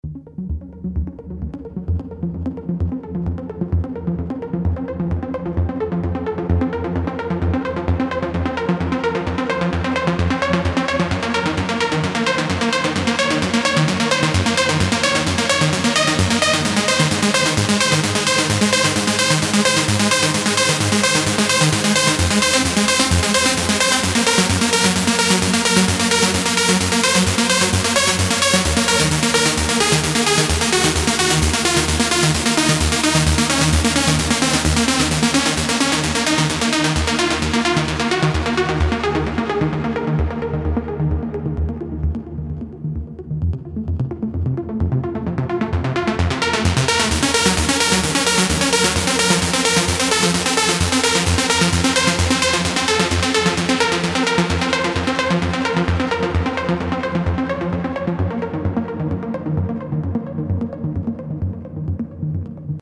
hmm ... also gerade mal den An1x durch die Effektkette der Diva gejagd.
Aber ich meine, dass das Besondere im Klang evtl das schlechtklingende Blech der alten VAs ist. Der An1x ist da dem JP ähnlich Anhang anzeigen An1xSupersaw.mp3